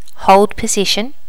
Additional sounds, some clean up but still need to do click removal on the majority.
hold position.wav